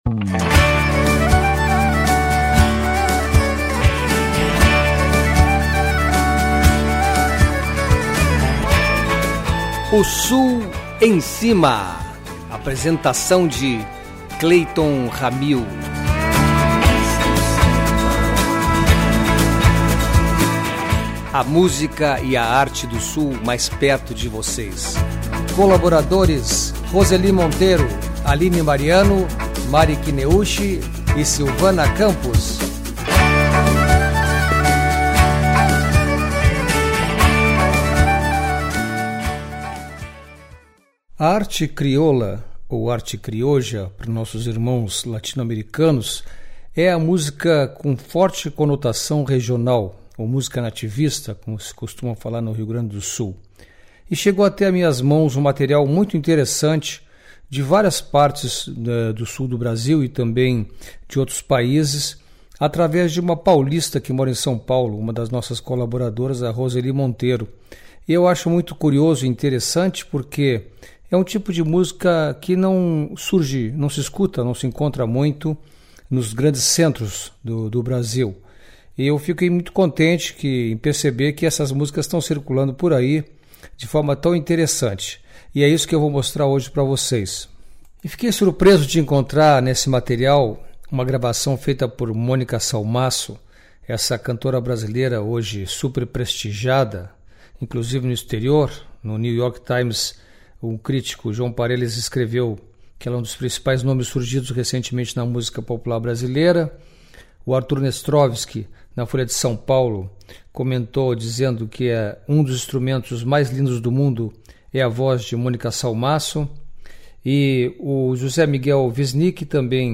Batuques como de rituais indígenas, instrumentos de corda levando ao cigano, gaita de herança espanhola e embalos de vozes melódicas.
Música Criolla ou Crioula